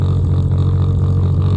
循环 " Drone2
描述：声码器的嗡嗡声载体
Tag: 循环 声码器载波 雄蜂